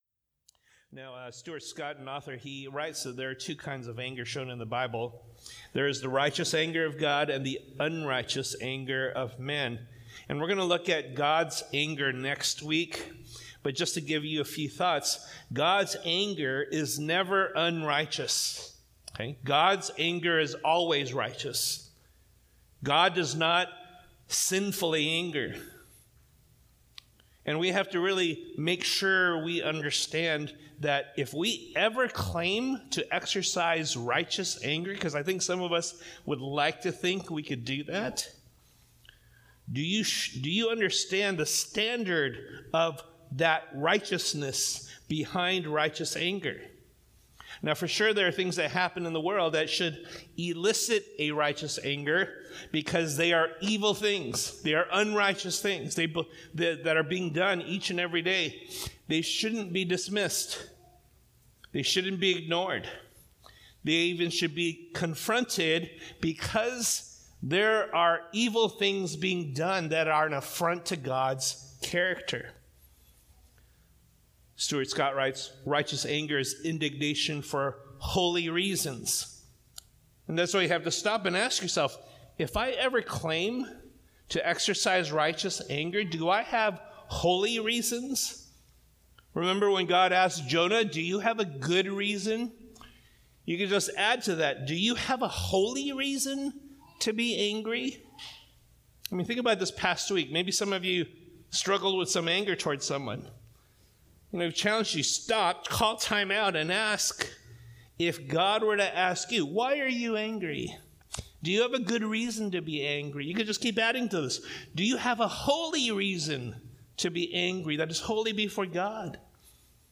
August 21, 2022 (Sunday Service)